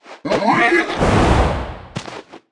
Media:Sfx_Anim_Ultimate_Hog Rider.wav 动作音效 anim 在广场点击初级、经典、高手、顶尖和终极形态或者查看其技能时触发动作的音效
Sfx_Anim_Ultimate_Hog_Rider.wav